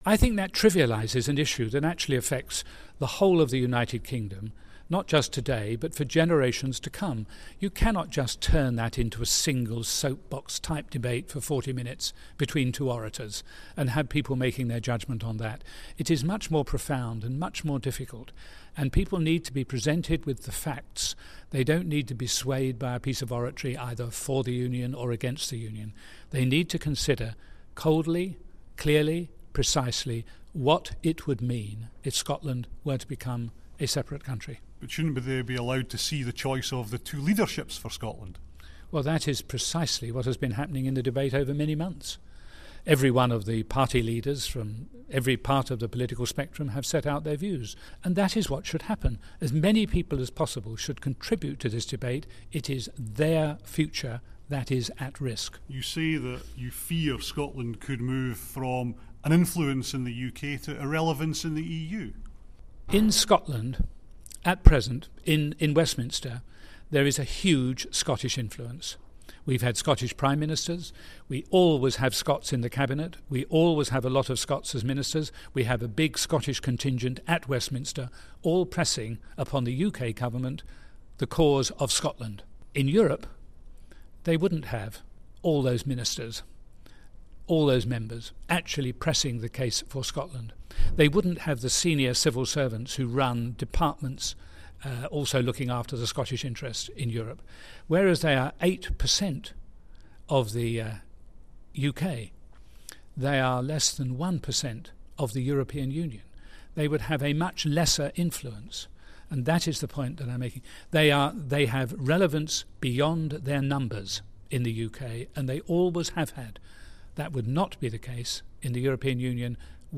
speaks to former Conservative Prime Minister Sir John Major who is resisting calls for a debate between David Cameron and Alex Salmond